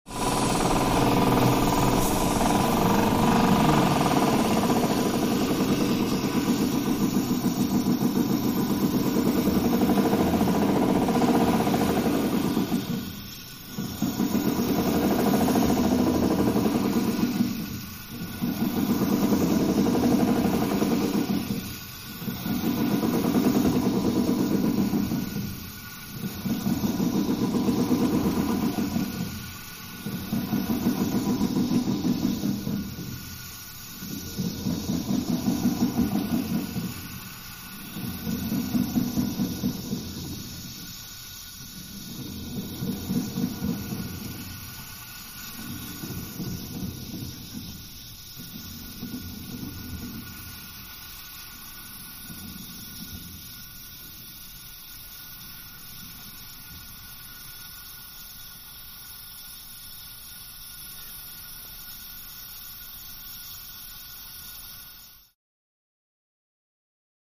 Oil Derrick; Winds Down, Off With Steam Hiss, Close Perspective